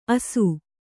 ♪ asu